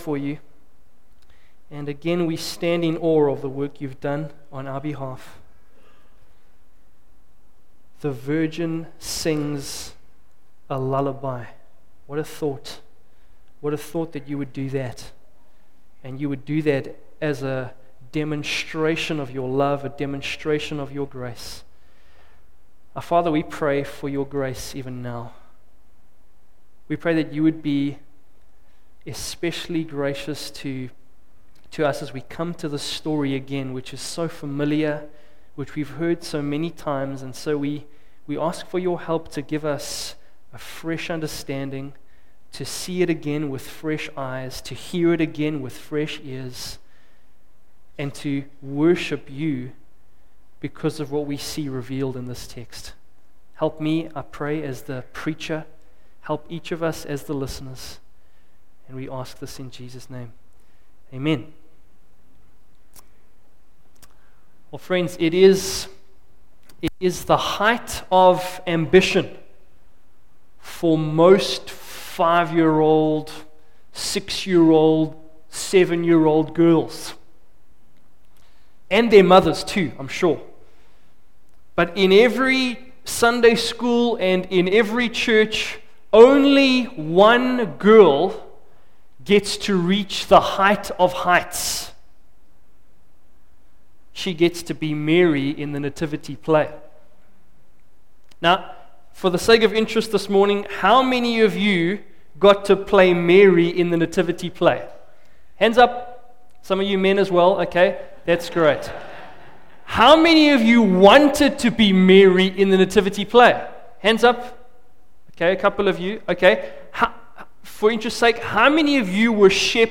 26-38 “Around the manger : Mary” Share this... Facebook Twitter email Posted in Morning Service